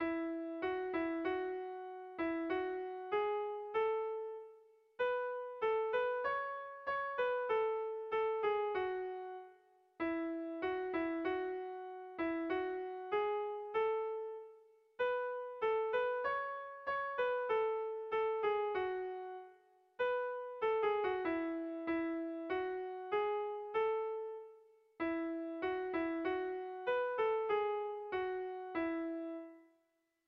Gabonetakoa
Atharratze < Atharratze-Sorholüze < Basabürüa < Zuberoa < Euskal Herria
ABABDE